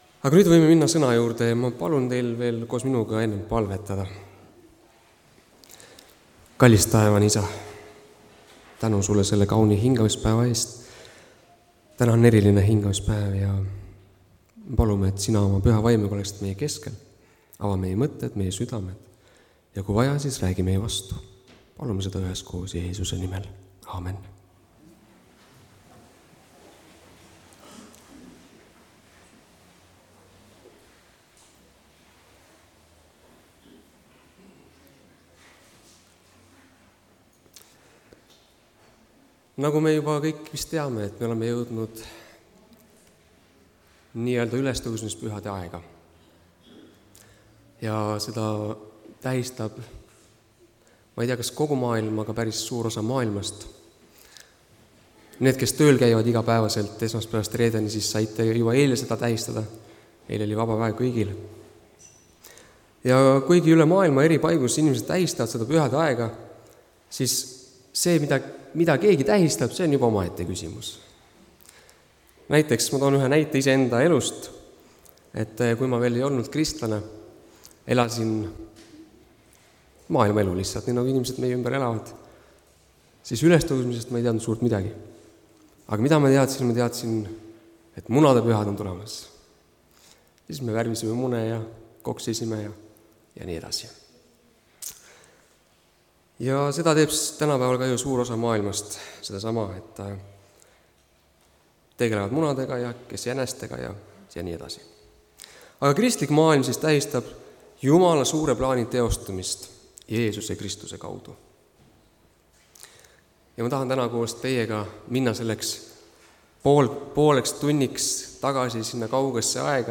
Jeesuse teekond (Tallinnas)
Jutlused